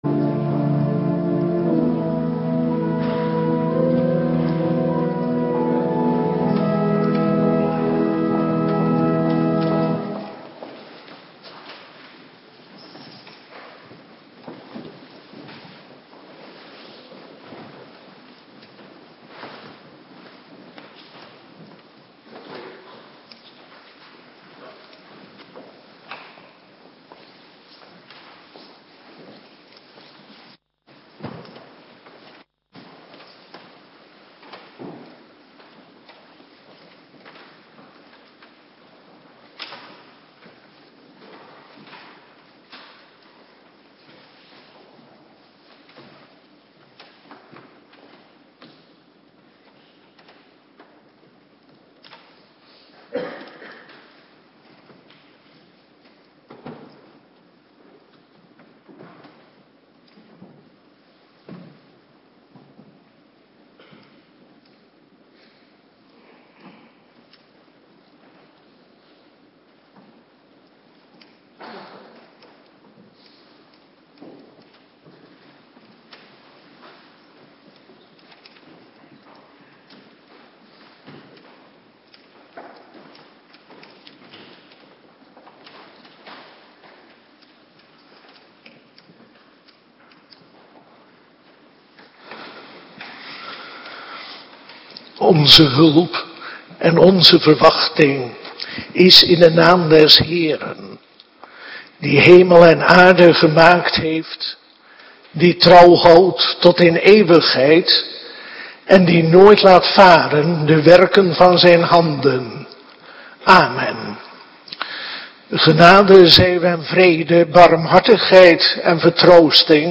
Morgendienst - Cluster 2
Locatie: Hervormde Gemeente Waarder